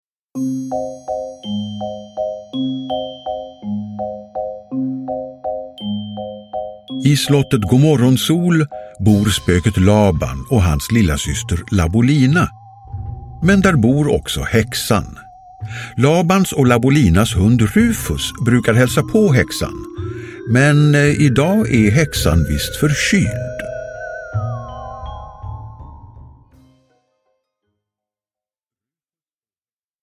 Lilla Spöket Laban: Rufus och häxan – Ljudbok – Laddas ner